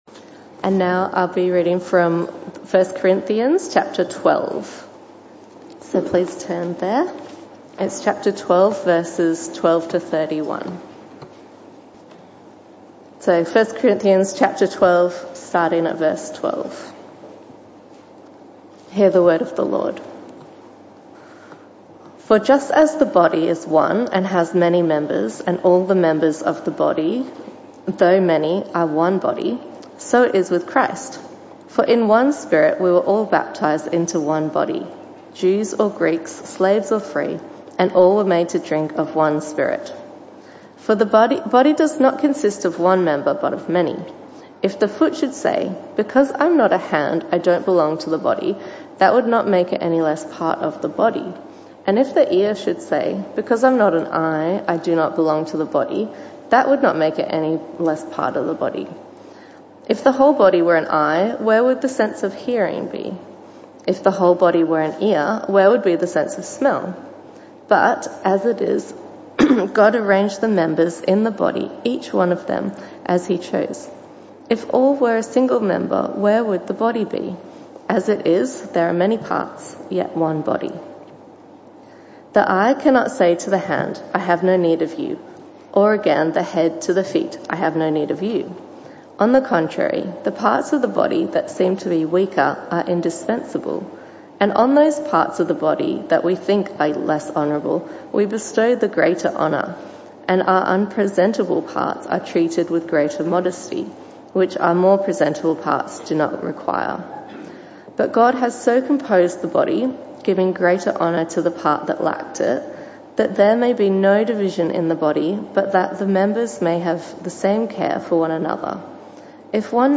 This talk was part of the AM/PM Service series entitled Loving Your Church.